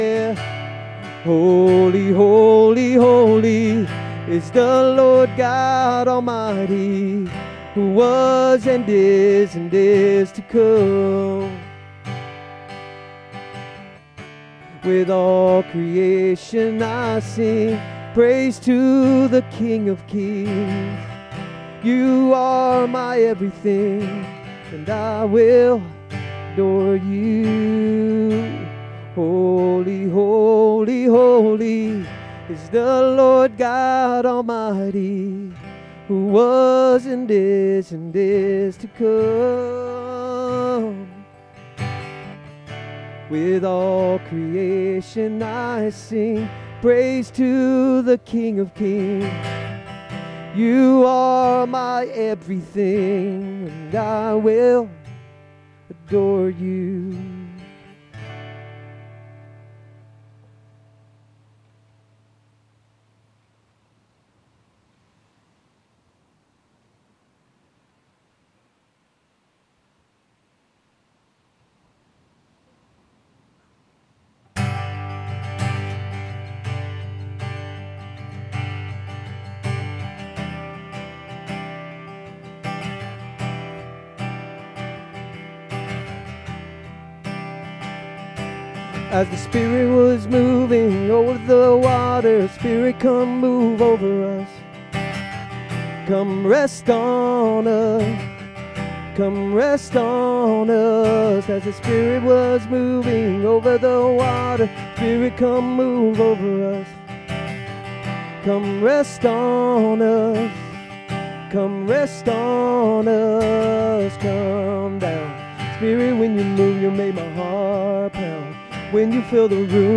SERMON DESCRIPTION Through God’s grace, all our sins are forgiven except one.